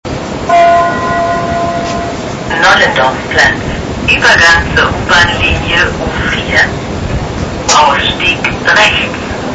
Departure Procedure and Sounds
Next-station announcements are like "[Dong] [name], Übergang zur U-Bahnlinie [line], Ausstieg rechts" ("[Dong] [name], transfer to subway line [line], exit on the right";